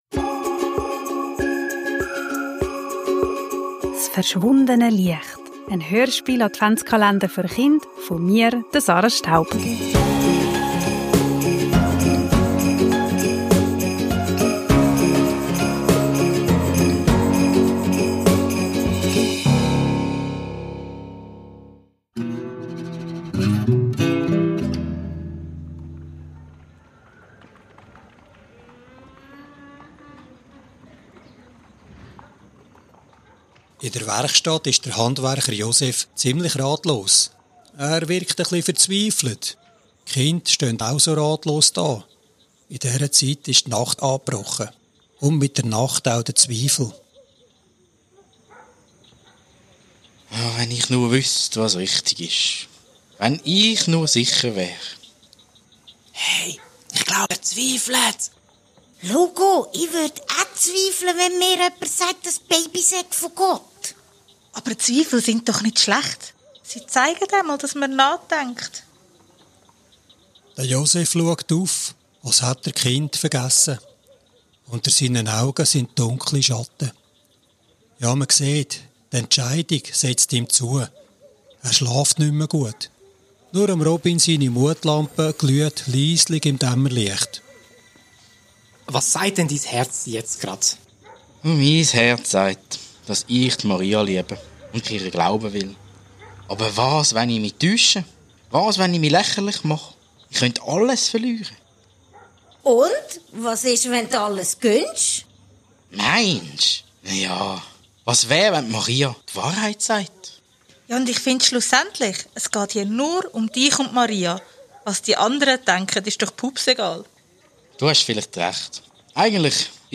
s' verschwundene Liecht - en Adventshörspiel-Kalender für Chind
Kinder, Advent, Hörspiel, Weihnachten, Kindergeschichte